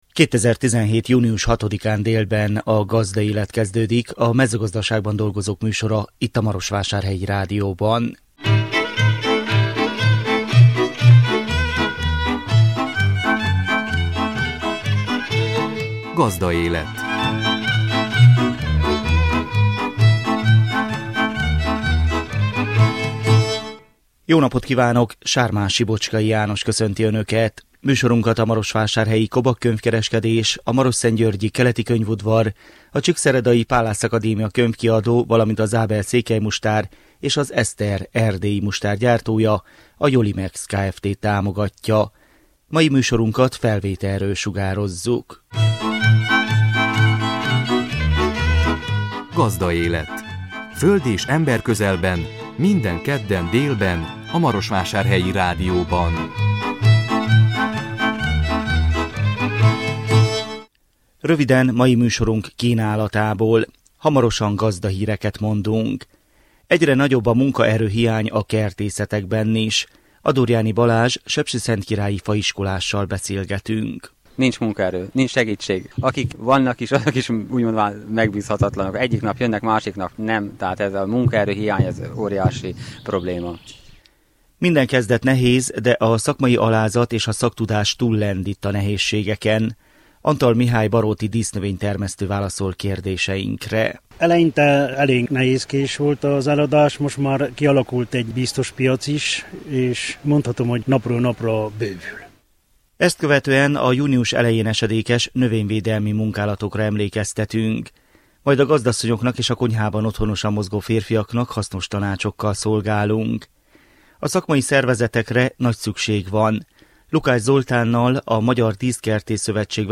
Gazdahírek